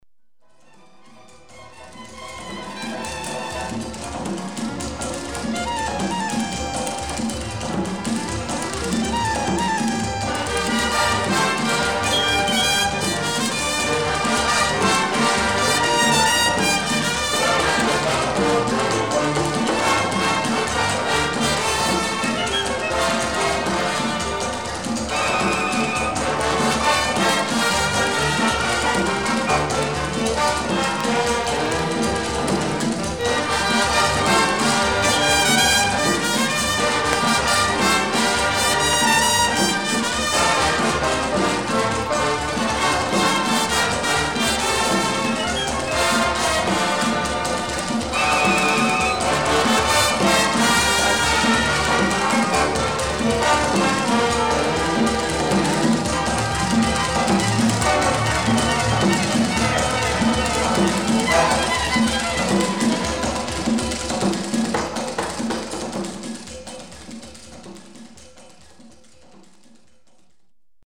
アフリカン　フォルクオーレを基礎とした、壮大なオ-ケストラの演奏が楽しめる作品